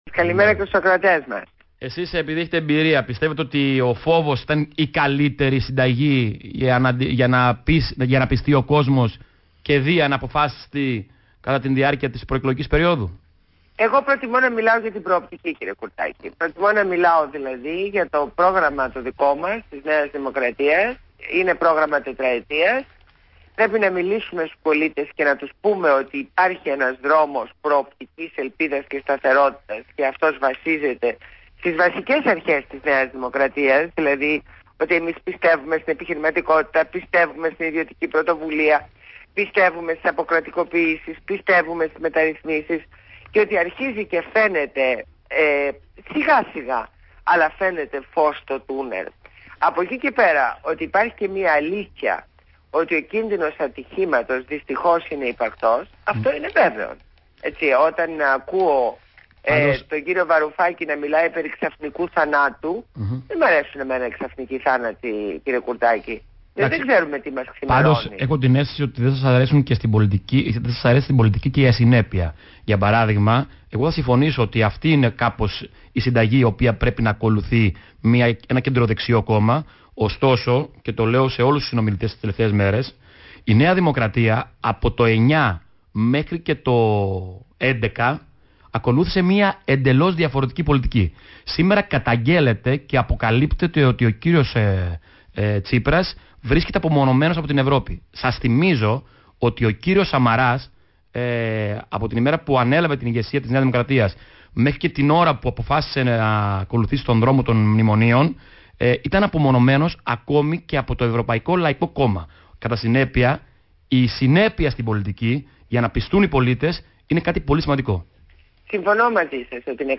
Συνέντευξη στο ραδιόφωνο Παραπολιτικά 90,1fm